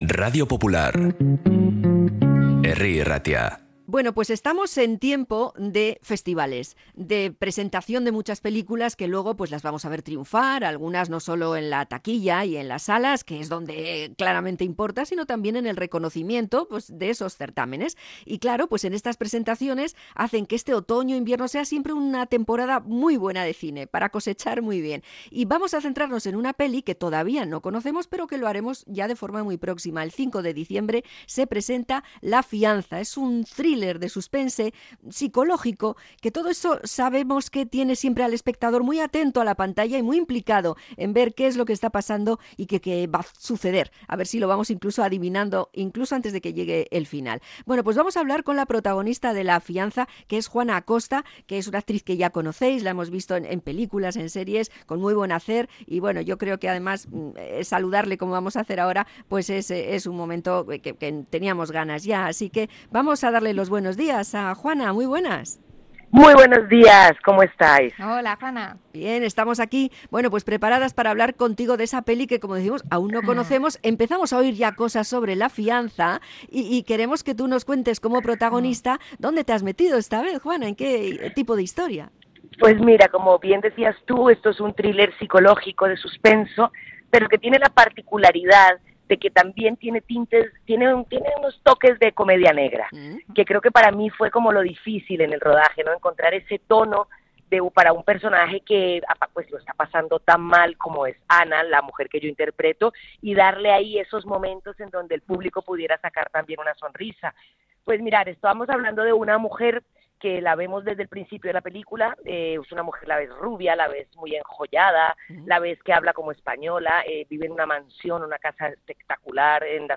Entrevista a Juana Acosta por 'La fianza'
JUANA-ACOSTA-ENTREVISTA-RADIO-POPULAR-BILBAO.mp3